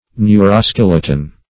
Meaning of neuroskeleton. neuroskeleton synonyms, pronunciation, spelling and more from Free Dictionary.
Search Result for " neuroskeleton" : The Collaborative International Dictionary of English v.0.48: Neuroskeleton \Neu`ro*skel"e*ton\, n. [Neuro- + skeleton.]